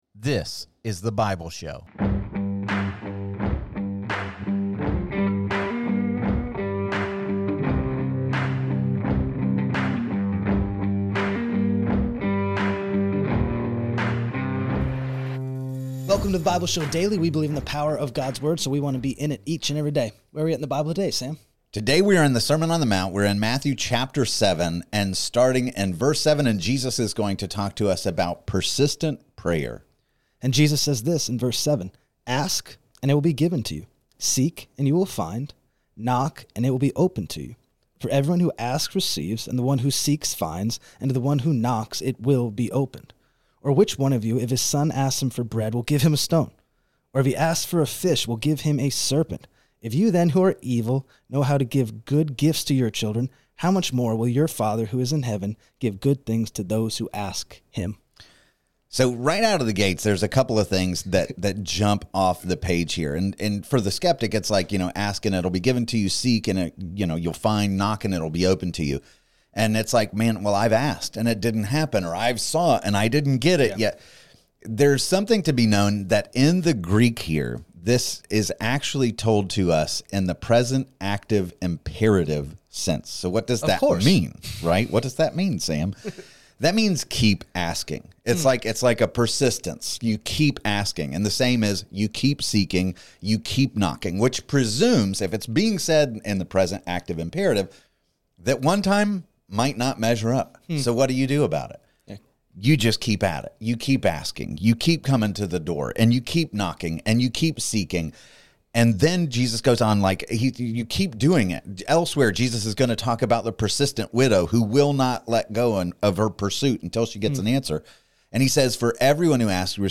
This podcast offers captivating discussions about various passages of the Bible -- examining their historical context, their relevance for modern life, and how these ancient stories point our hearts and minds to Jesus.